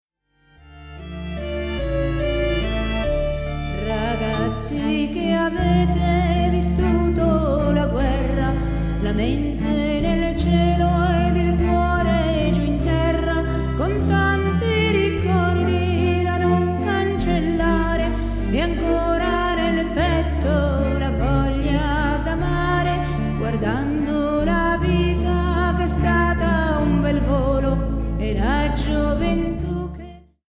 sonorità liriche e orchestrali
ud, darbuka, bandurria...